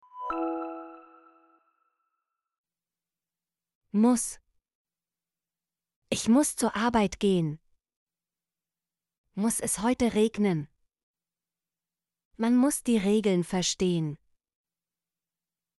muss - Example Sentences & Pronunciation, German Frequency List